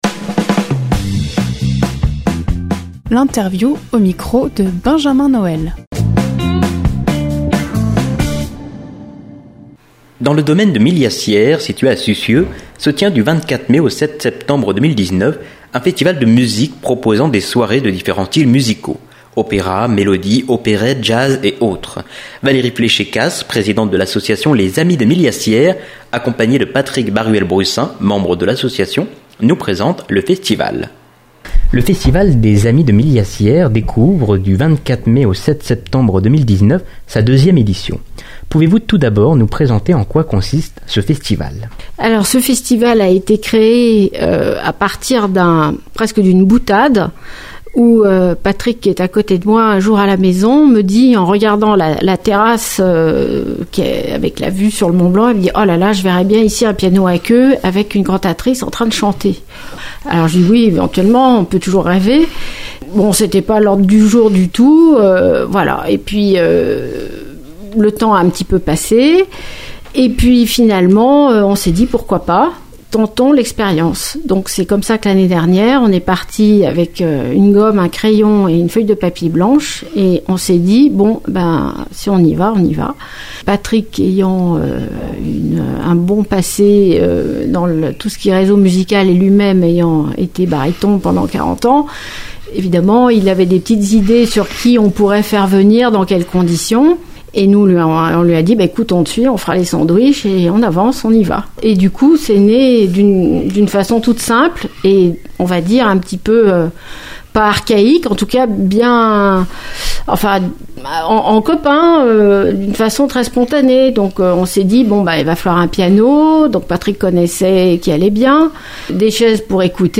interview-vfc-sur-radio-couleur-fm-du-3-juin-2019.mp3